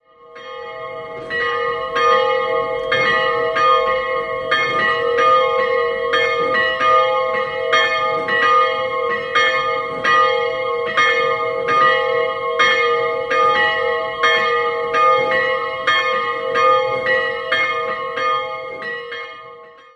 Die beiden Zuckerhutglocken im Dachreiter
Innenansicht leider nicht vorhanden. 2-stimmiges Geläute: h''-c''' Im Turm hängt eine Glockenrarität ersten Ranges: Zwei Zuckerhutglocken vom Anfang des 13. Jahrhunderts.
Quedlinburg_Hospitalkapelle.mp3